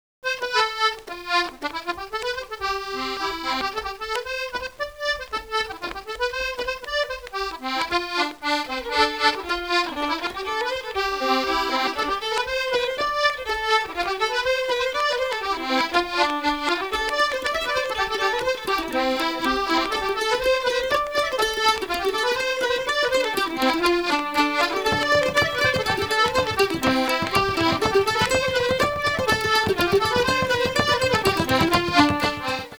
Irish Music
accordion
accordion.wav